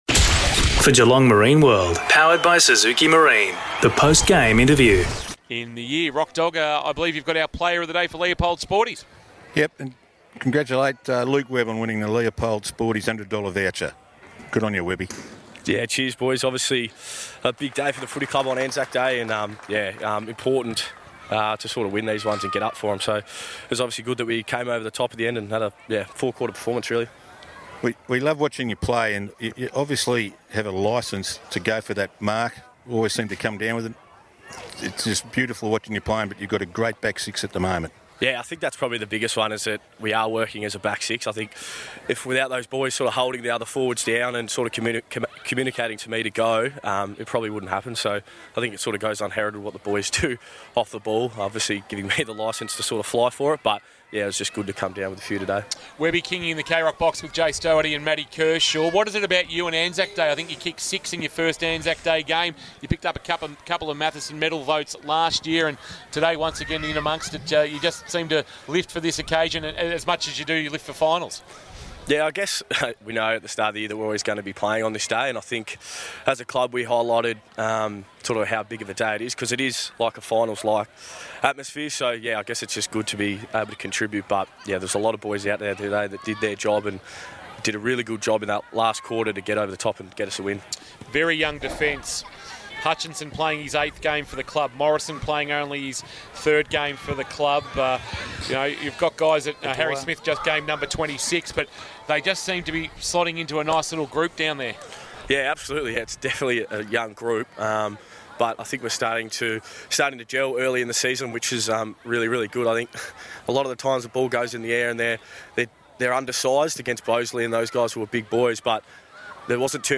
2022 - GFL ROUND 3 - ST JOSEPH'S vs. SOUTH BARWON: Post-match Interview